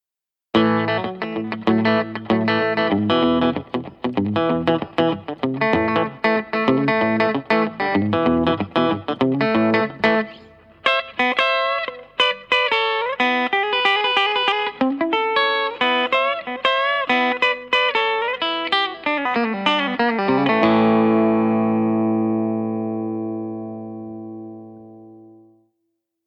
Une guitare BOW télécaster Blackguard Butterscotch, avec un beau manche en érable flammé, équipée d’un P90 en grave et d’un micro telecaster aigu, de chez Hepcat Pickups.Parfaite pour la country , en position aigu, et bien pour le jazz en position grave….
Micro aigu